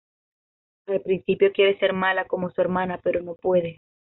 Pronunciado como (IPA) /eɾˈmana/